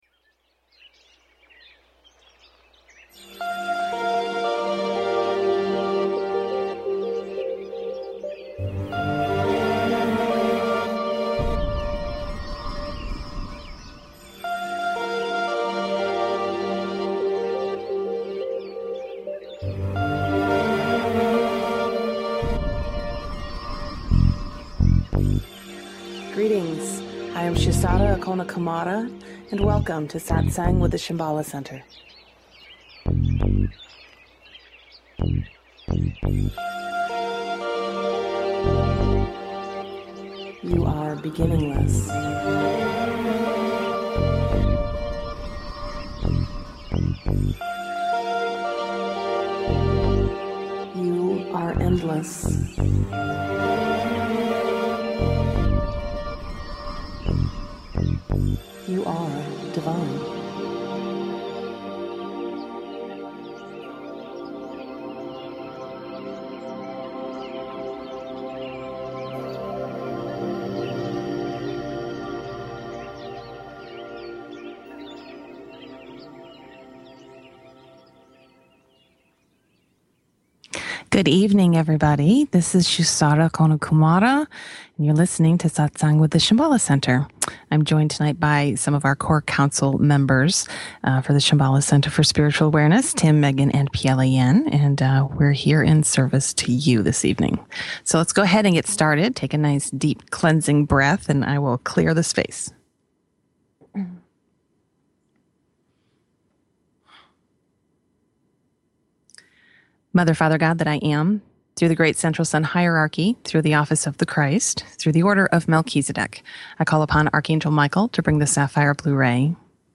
Talk Show Episode
Also one of our listeners called in to ask a question about how energy becomes disqulaified and its relation to the chakras.